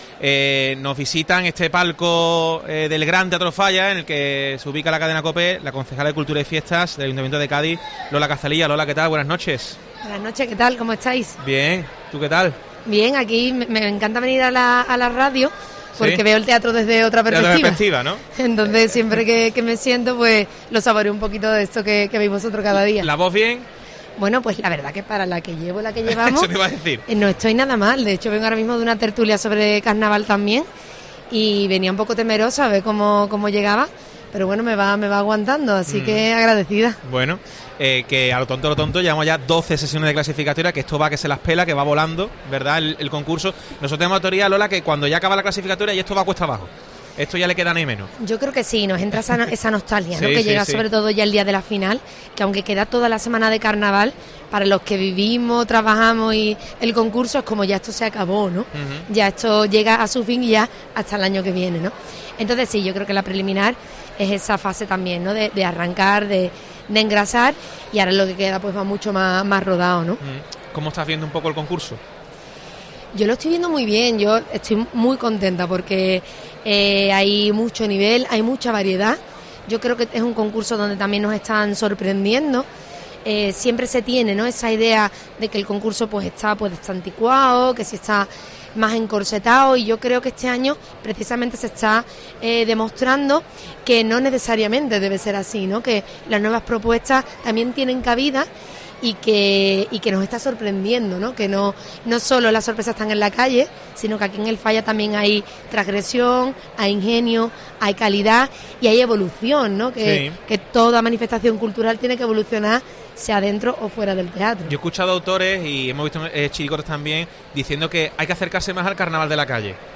La concejala de Cultura y Fiestas del Ayuntamiento de Cádiz, Lola Cazalilla, ha estado en los micrófonos de COPE desde el Gran Teatro Falla.
ESCUCHA LA ENTREVISTA A LOLA CAZALILLA EN COPE Uno de los asuntos importantes de la fiesta es la lucha contra el machismo, que por desgracia sigue existiendo en la sociedad y también en el carnaval.